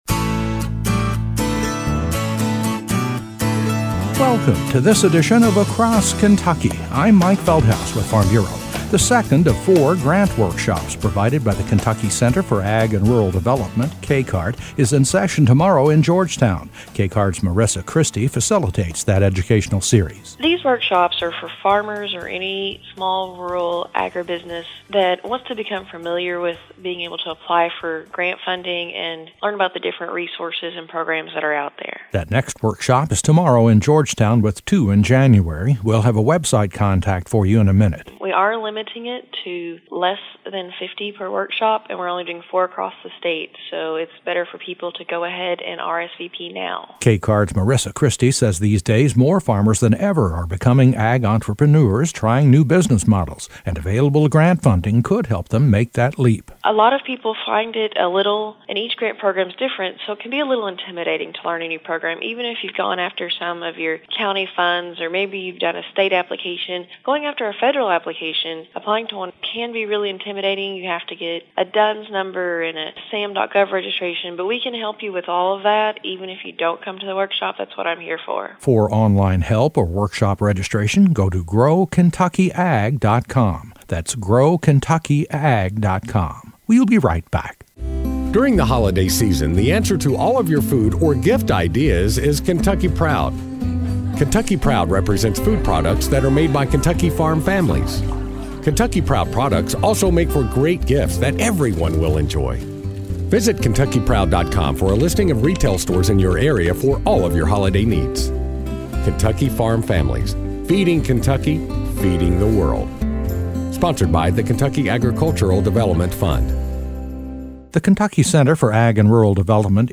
A report on a series of grant writing workshops underway through January, hosted by the Ky Center for Ag and Rural Development (KCARD).